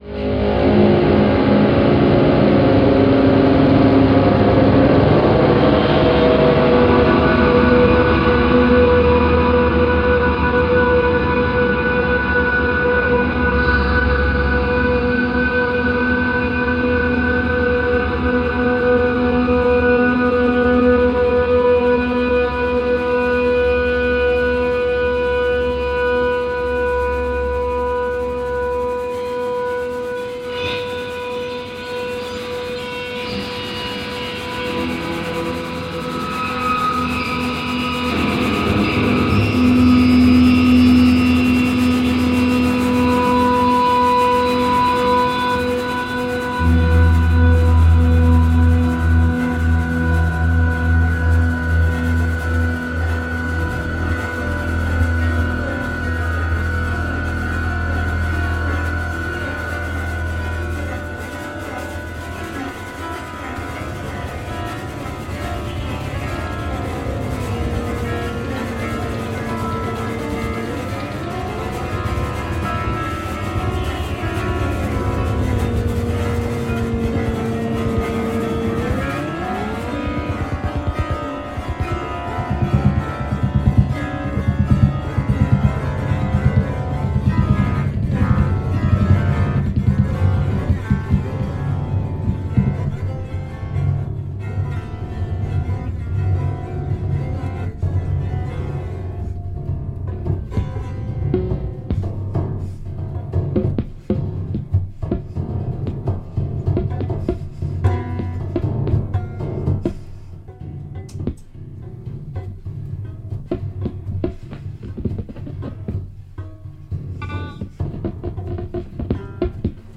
piano
electric guitar